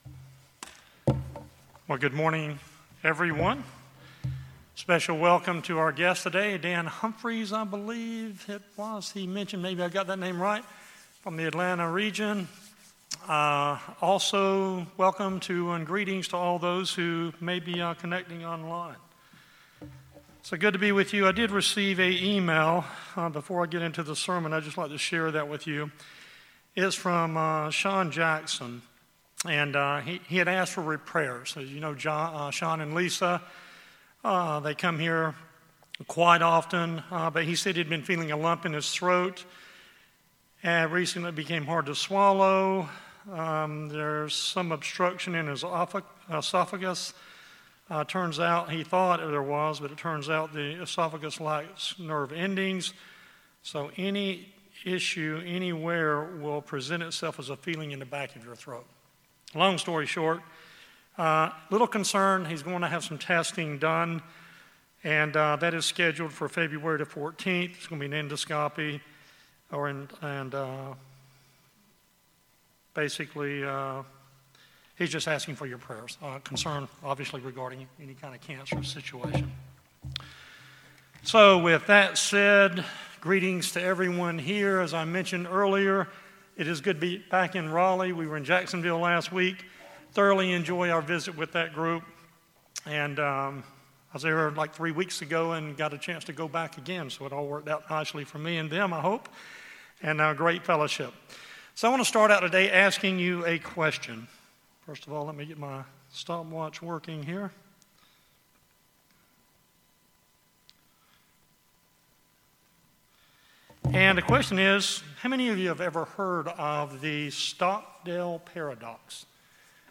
Given in Jacksonville, NC Raleigh, NC